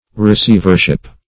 Receivership \Re*ceiv"er*ship\, n.